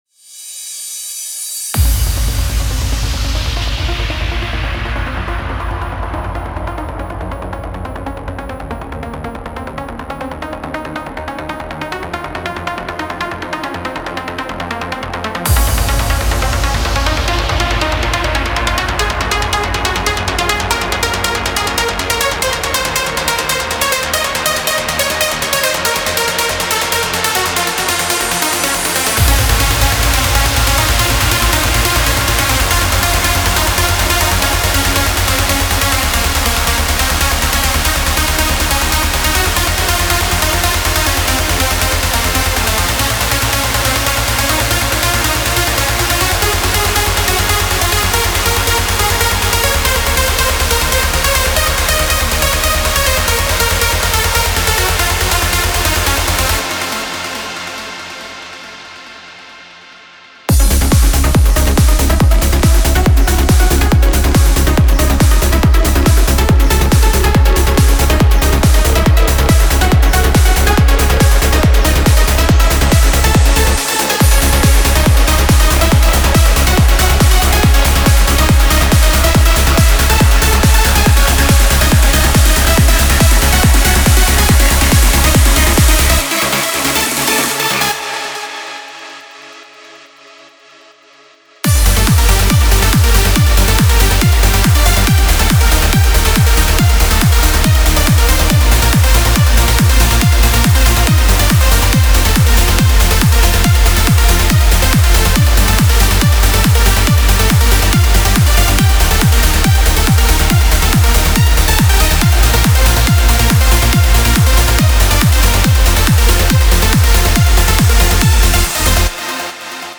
Genre: Trance Uplifting Trance